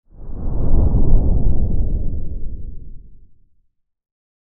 Intense Deep Rumbling Whoosh Transition Sound Effect
Description: Intense deep rumbling whoosh transition sound effect. Cinematic low swoosh sound, perfect for enhancing the intensity of a transition in a video or game.
Intense-deep-rumbling-whoosh-transition-sound-effect.mp3